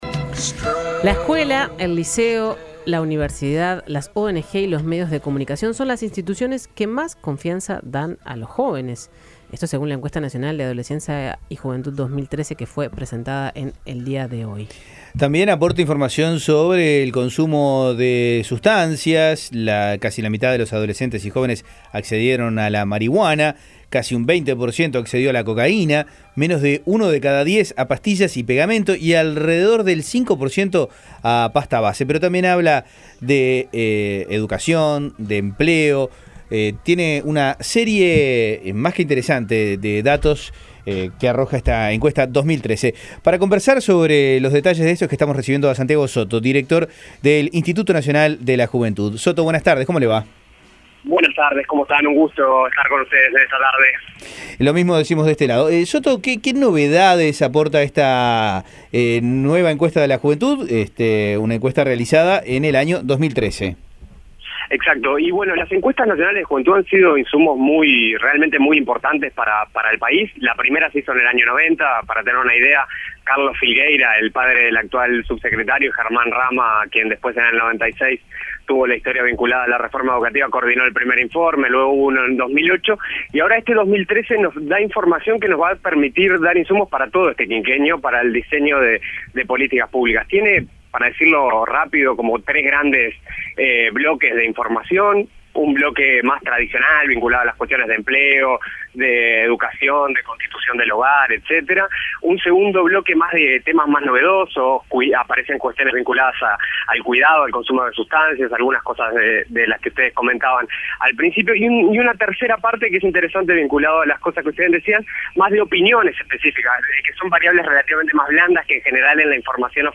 En 810 Vivo entrevistamos a Santiago Soto, director del Instituto Nacional de la Juventud .